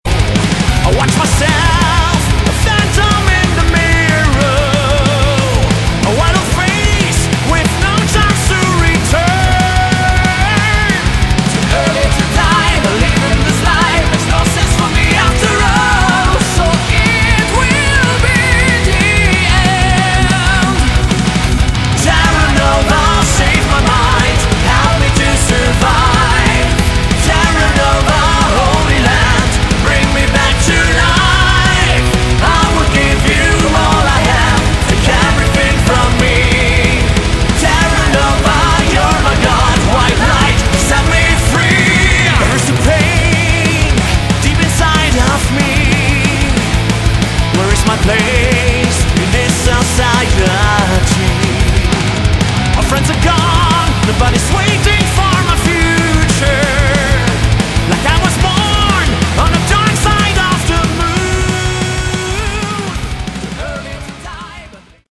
Category: Melodic Metal
vocals
guitar
drums
bass